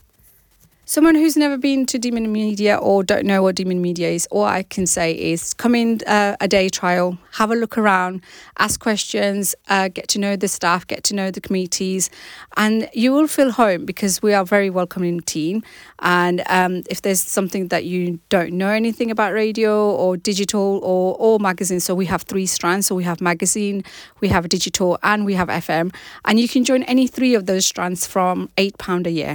29-second-audio-news-clip_mixdown.mp3